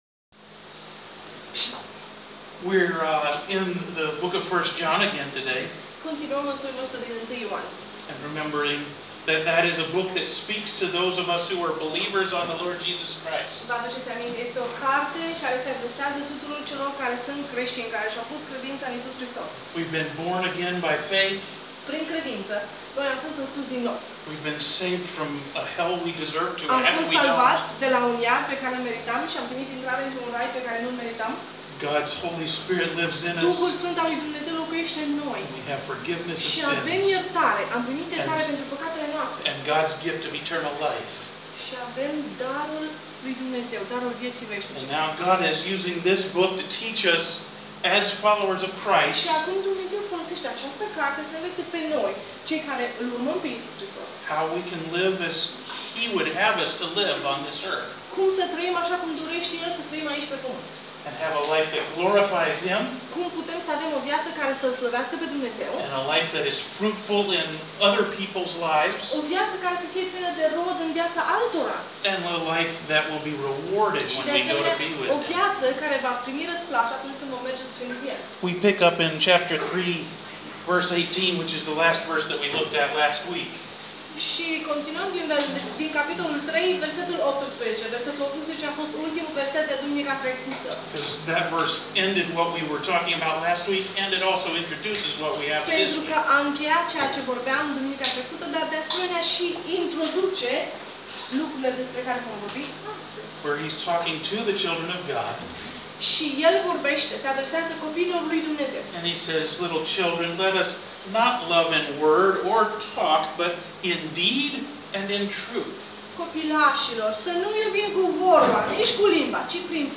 Sermon audio 16 iunie – 1 Ioan 3:18-24